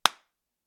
spank.ogg